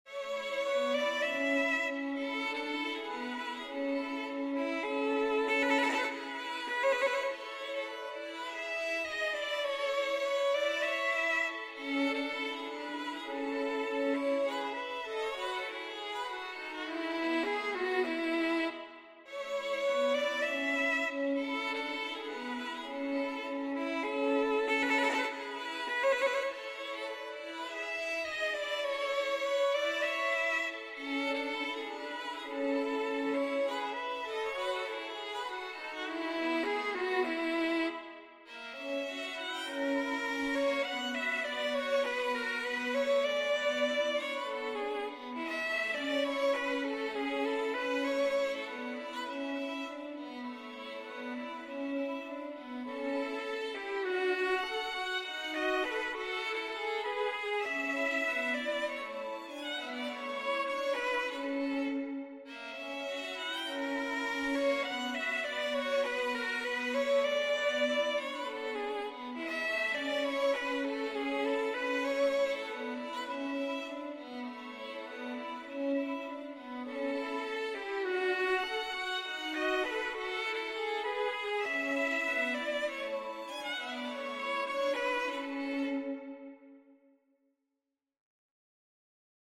MIDIBologne, Joseph, Six Sonatas for two violins book 2, Sonata No. 3, mvt.
Aria con variatione, mm.1-18